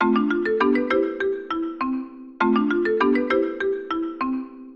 phone_bell.mp3